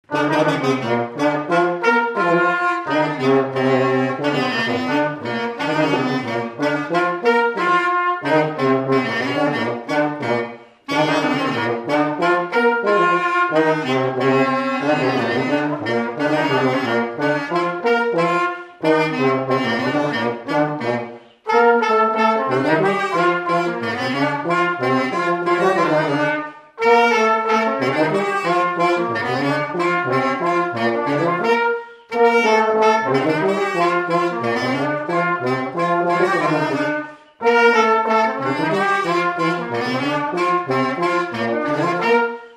Scottish
Chants brefs - A danser
Résumé instrumental
danse : scottish (autres)
Pièce musicale inédite